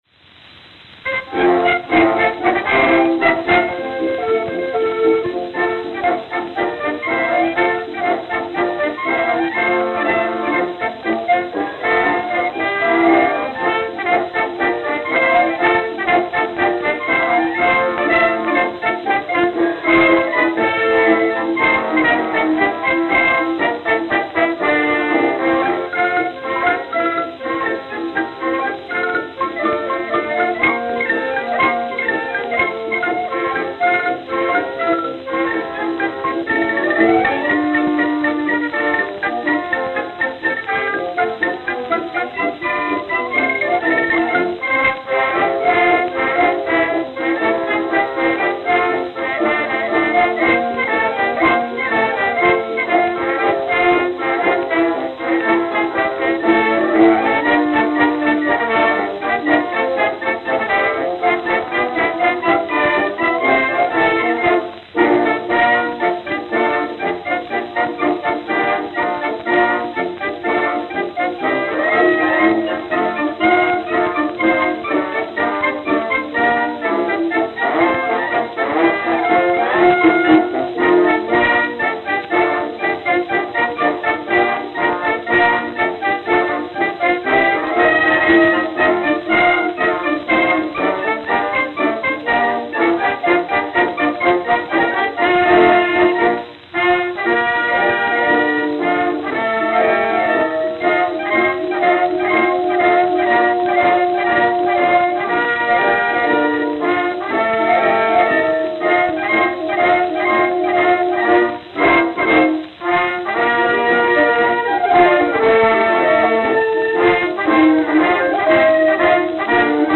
Victor 10-Inch Double-Sided Acoustical Records